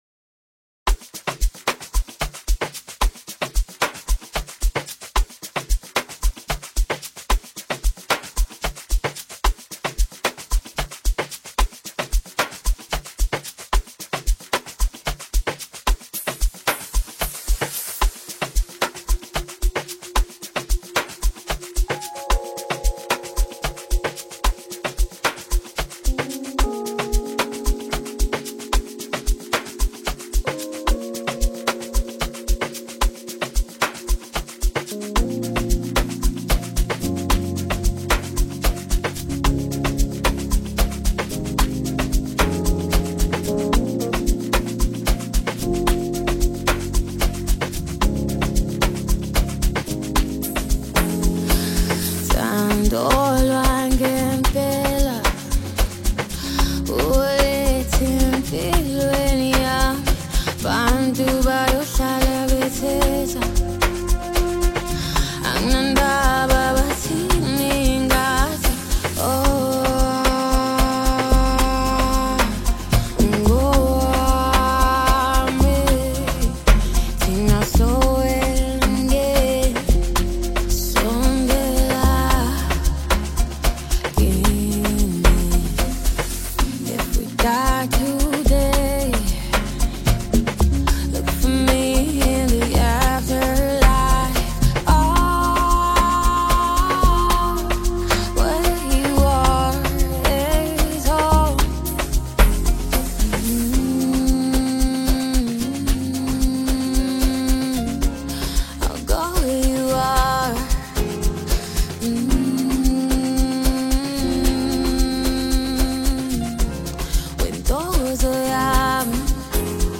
features a very skilled vocal